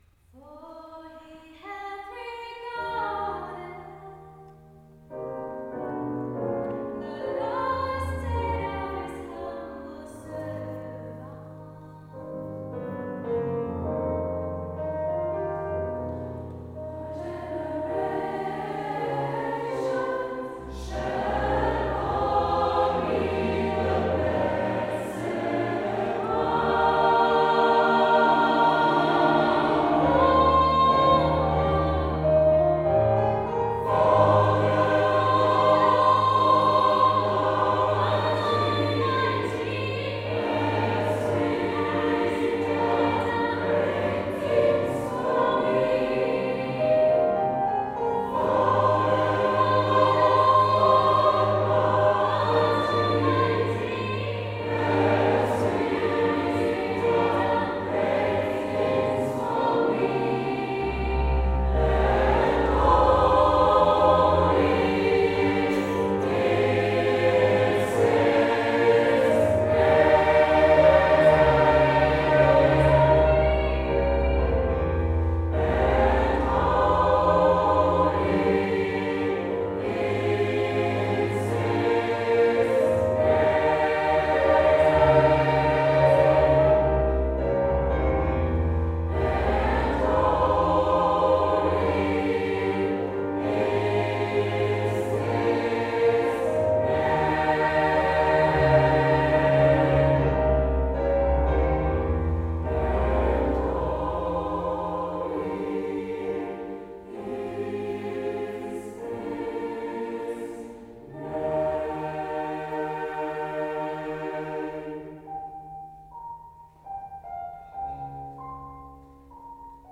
Meldung Gelungenes Gospelkonzert am Frauenberg Mittwoch, 02.
Gospelchor
mit einem Jazz- und Gospelkonzert
Kurz nach 17 Uhr begann das Konzert unter dem Leuten der Glocken mit dem Einzug des Chors in unseren Kirche, die Kirche war gut besucht und was danach folgte kann ich nur von meiner Stelle aus als schön bezeichnen. Sehr stimmgewaltig und perfekt umgesetzt setzte der Chor aus Uetersen die vorgetragenen Werke um und schon bald machte sich ein Lächeln auf den Gesichtern aller